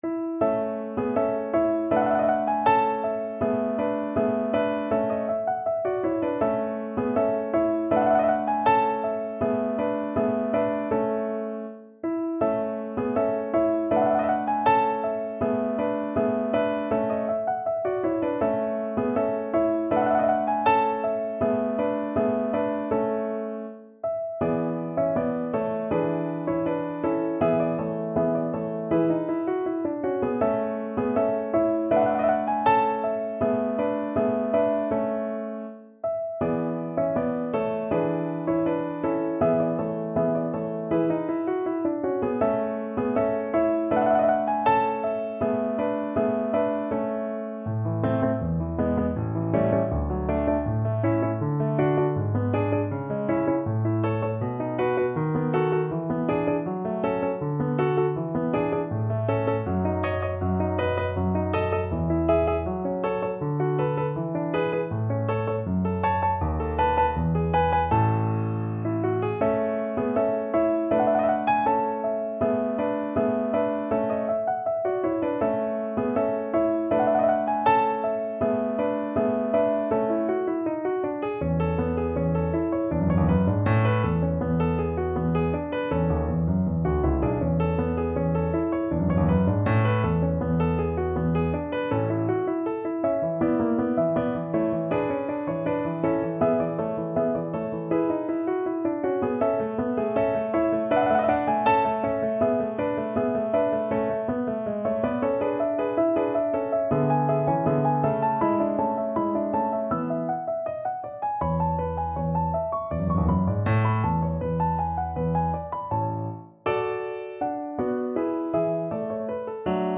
No parts available for this pieces as it is for solo piano.
2/4 (View more 2/4 Music)
Andante
Classical (View more Classical Piano Music)